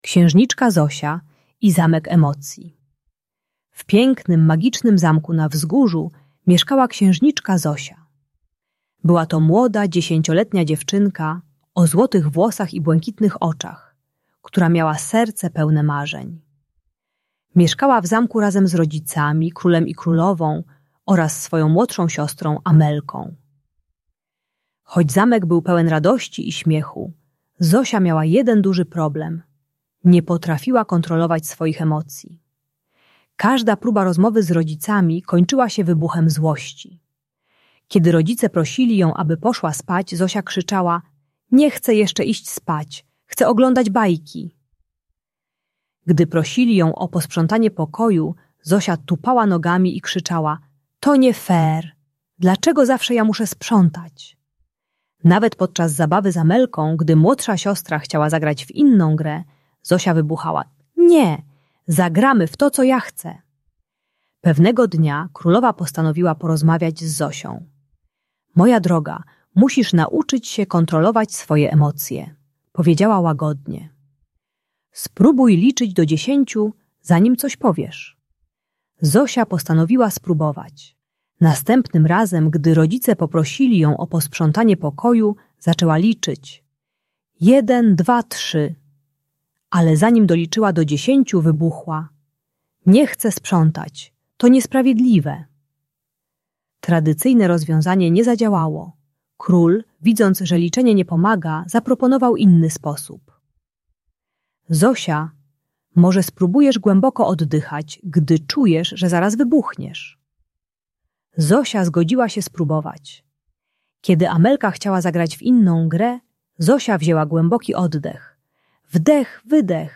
Uczy techniki wizualizacji "Zamku Emocji" - rozpoznawania i oswajania złości poprzez rozmowę z emocją zamiast wybuchania. Audiobajka o agresji do rodziców.